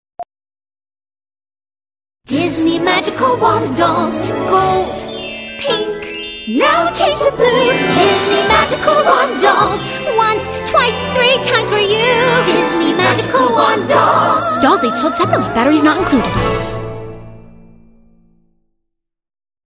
Demo Samples: Disney and Pop